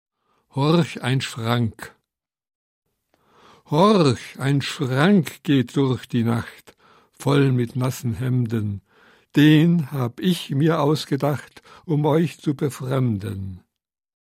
Gelesen und gesungen von Wiglaf Droste.